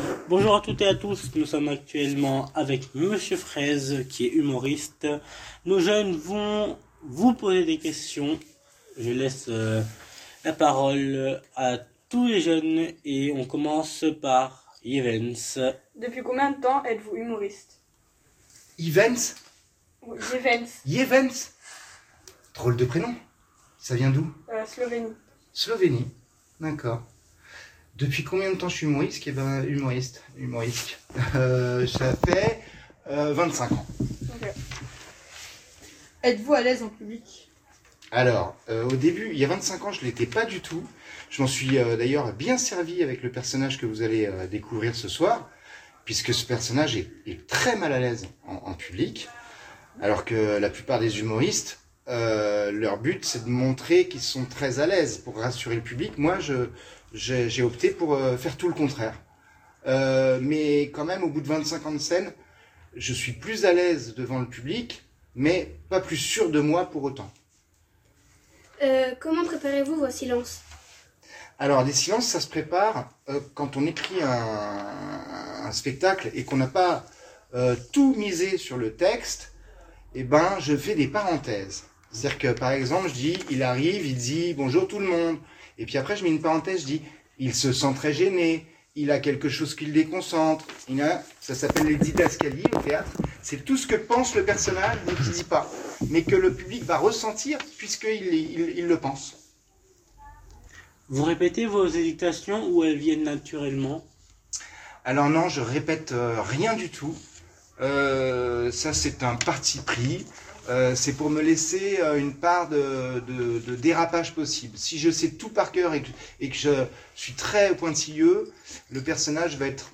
Marc_Fraize-ITW_Zygo_2026.ogg